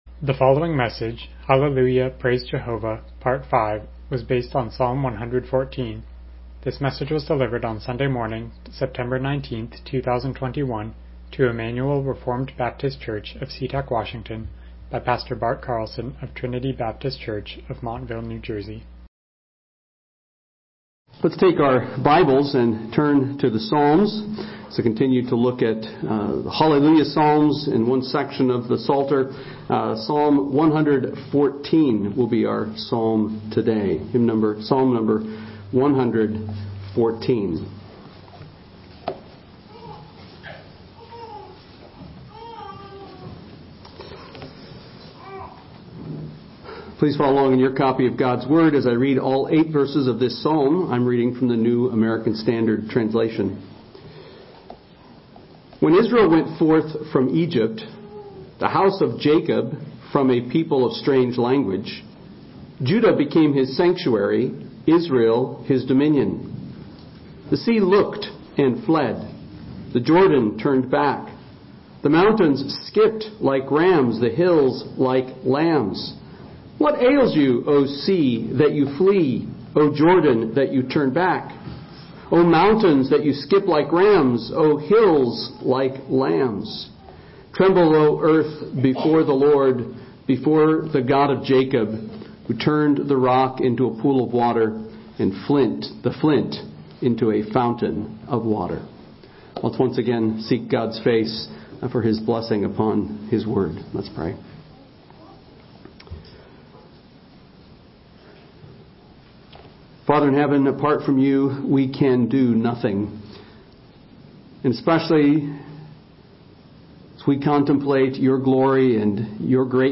Passage: Psalm 114 Service Type: Morning Worship « Conform Me